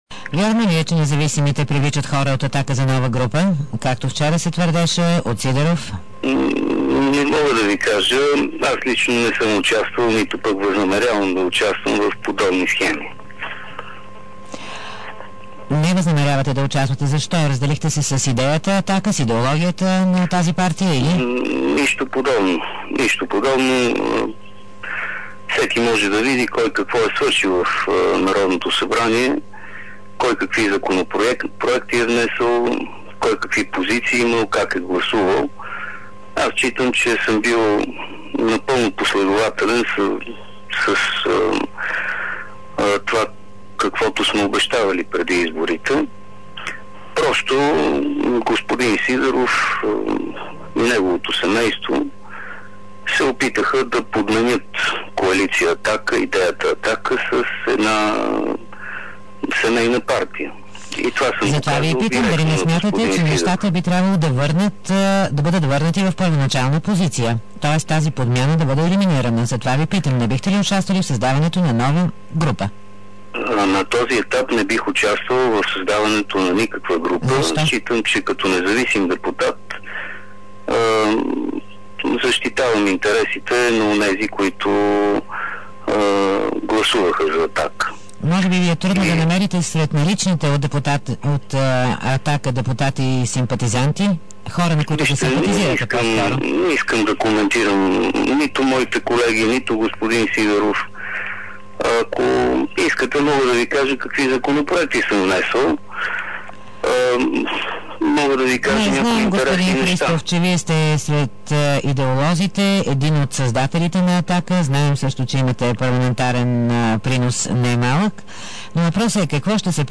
Минчо Христов, независим депутат в предаването „Дарик кафе”